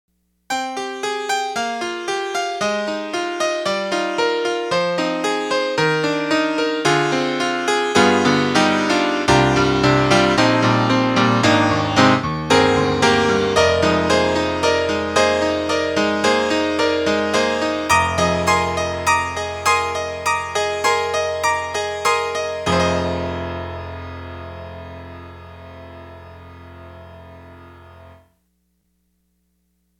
特集：徹底比較！DTM音源ピアノ音色聴き比べ - S-studio2